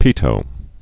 (pētō, pē-tō)